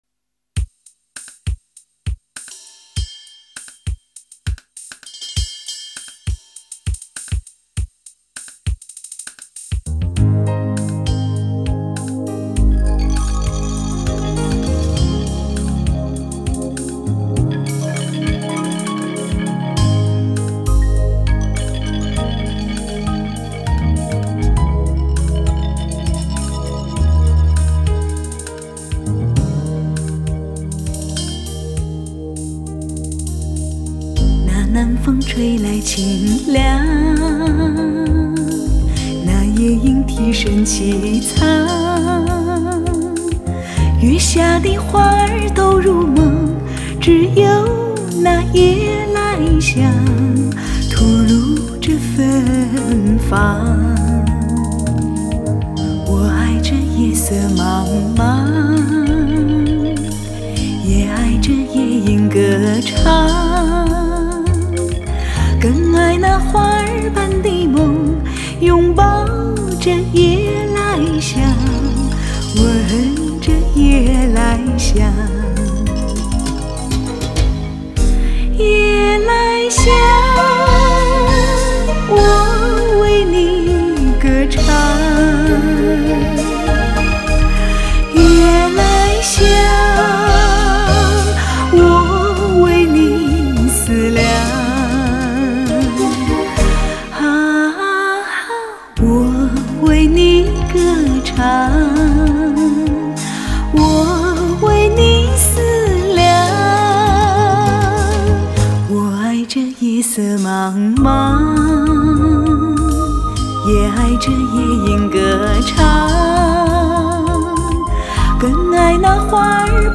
十三首耳熟能详的中国经典民谣更创新意的重新编配